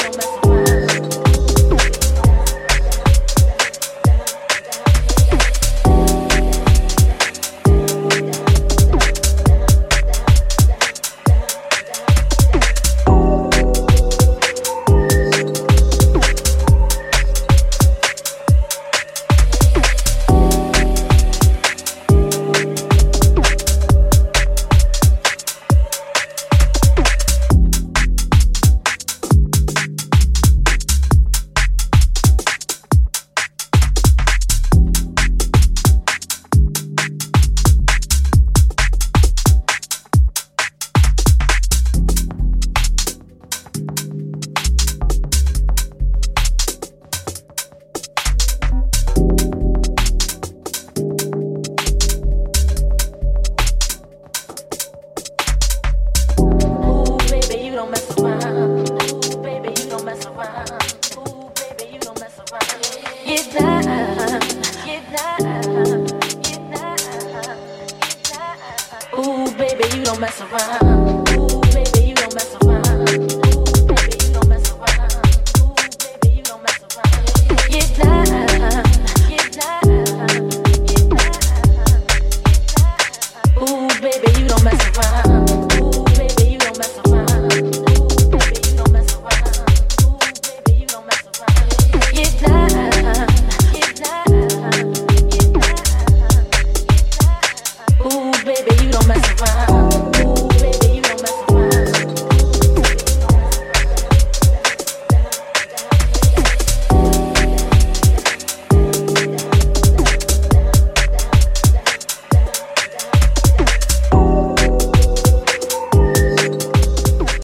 supplier of essential dance music
Soul Dub Reggae Funk